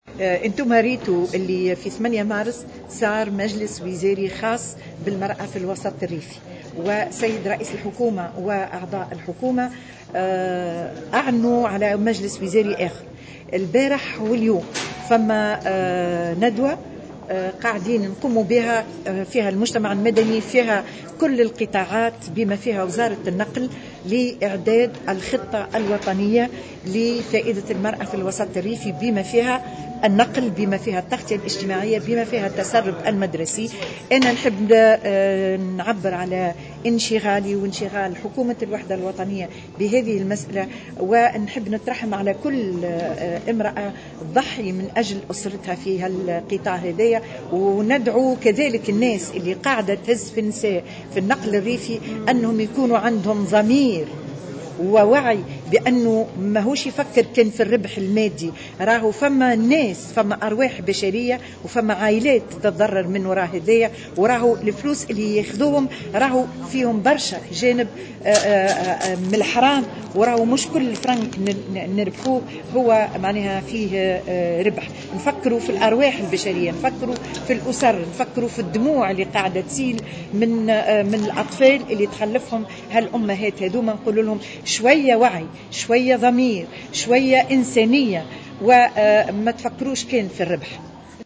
وأشارت الوزيرة في تصريح لمراسل الجوهرة اف ام خلال ندوة صحفية خاصة بعرض تقرير نشاط مندوبي حماية الطفولة لسنة 2016، إلى أن رئيس الحكومة أعلن عن مجلس وزاري ثان سيلتئم حول المرأة الريفية، بعد أول مجلس انتظم يوم 8 مارس الجاري، مؤكدة انشغال حكومة الوحدة الوطنية بوضع المرأة في الوسط الريفي.